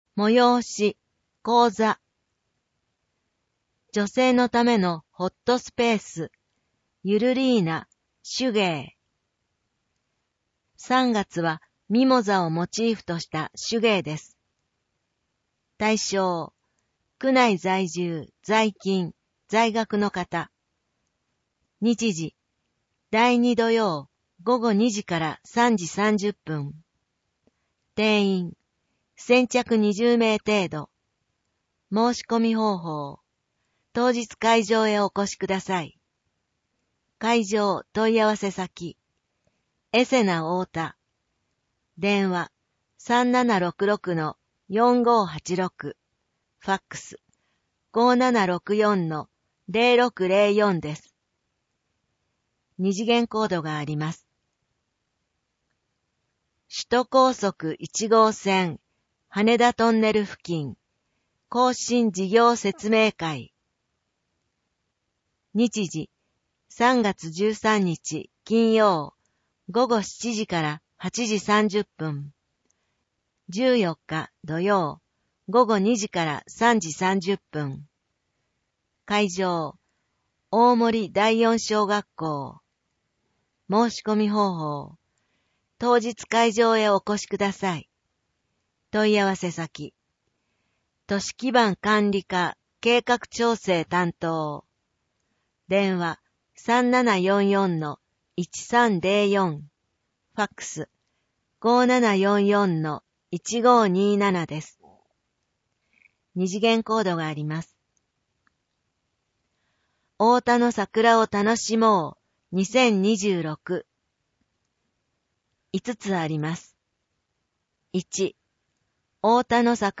なお、この音声版は、障がい者総合サポートセンター声の図書室で製作したCDを再生したものです。